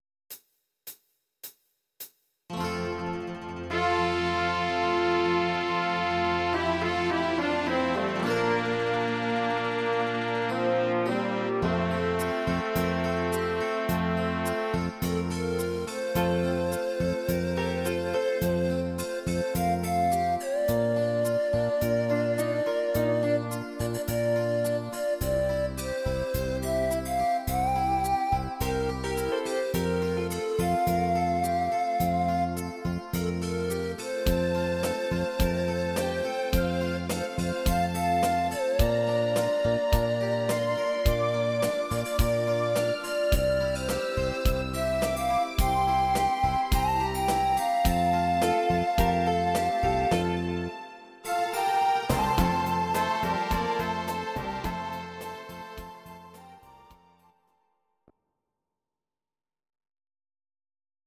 Audio Recordings based on Midi-files
German, Medleys, 1970s